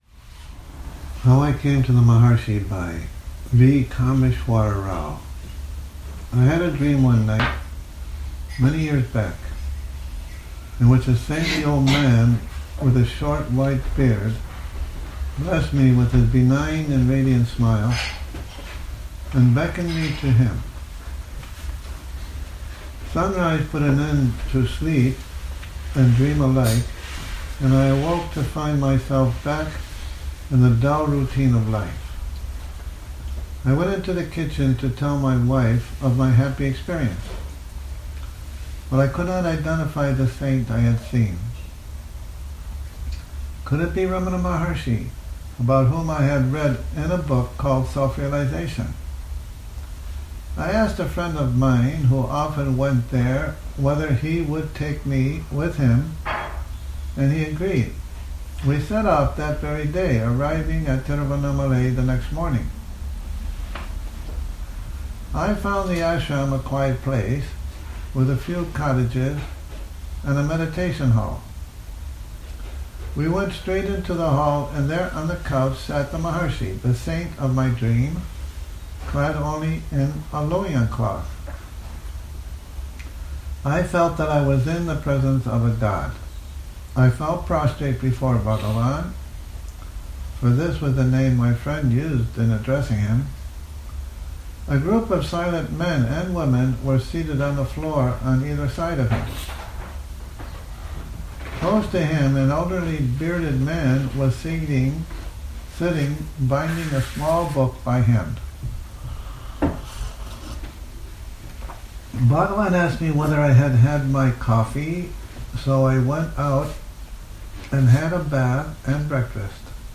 Morning Reading, 10 Nov 2019